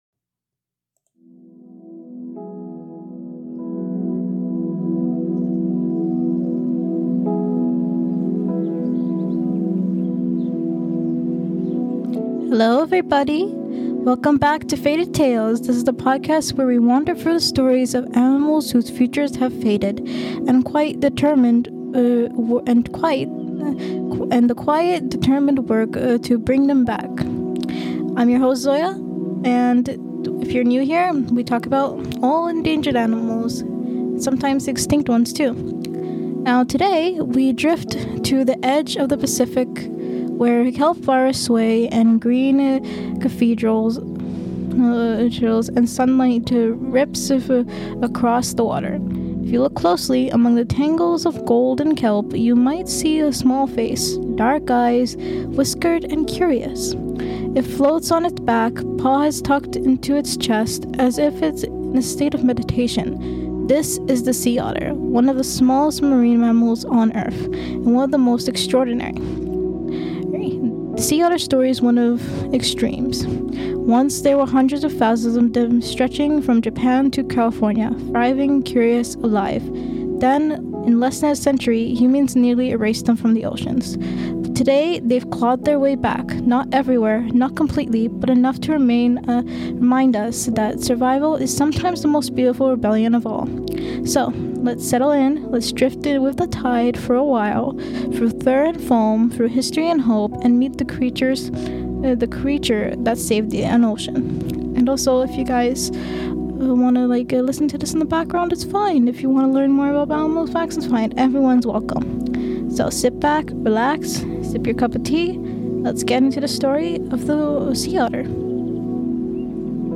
Once hunted to the brink of extinction for its luxurious fur, the sea otter now floats as a symbol of resilience and renewal. In this calm and reflective episode of Faded Tails, we explore the hidden world of these remarkable marine mammals. From their intricate social lives and ingenious use of tools to their vital role in maintaining the balance of ocean ecosystems.